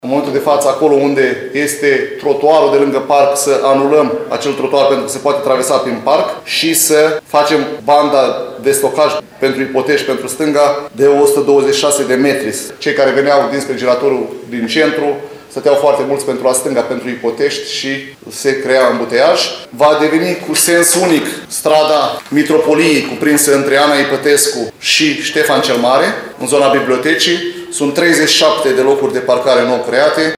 Viceprimarul LUCIAN HARȘOVSCHI a precizat că lucrările sunt programate să dureze 3 săptămâni.